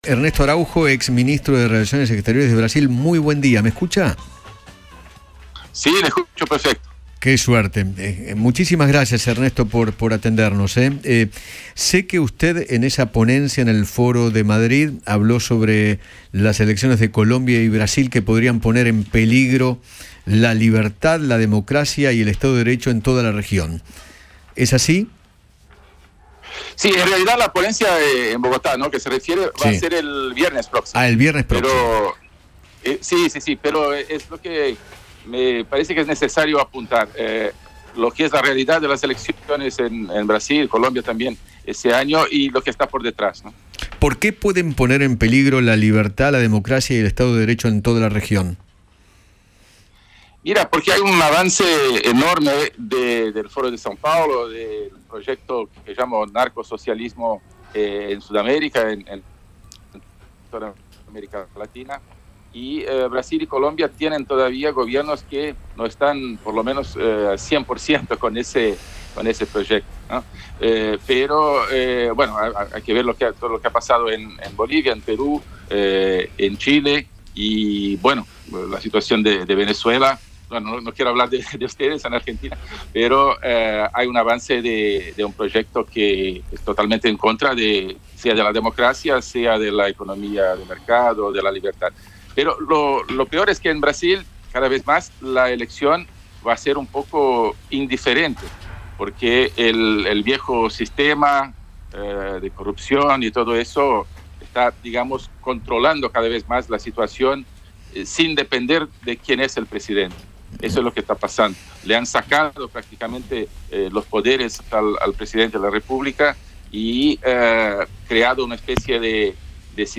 Ernesto Araújo, exministro de Relaciones Exteriores de Brasil, habló con Eduardo Feinmann acerca de la situación política de la región, las elecciones presidenciales en aquel país y la visita de Bolsonaro a Rusia.